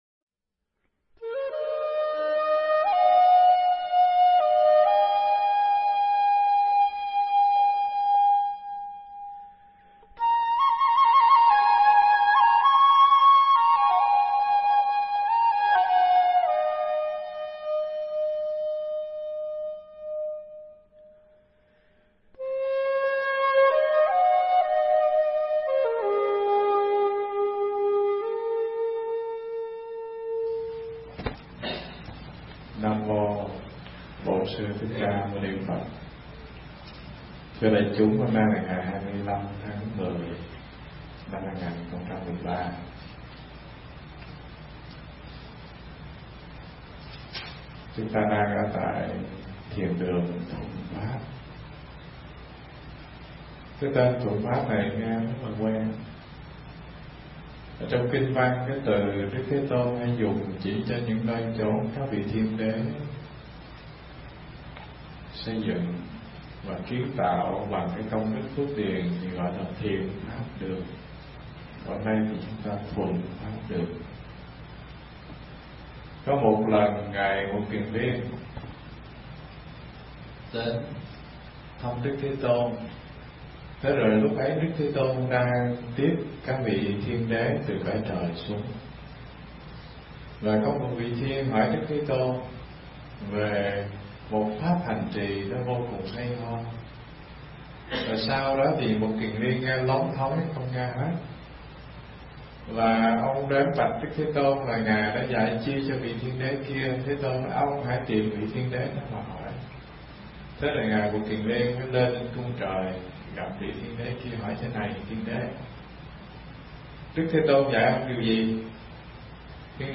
Nghe Mp3 thuyết pháp Tùy Thuận Pháp Phần 1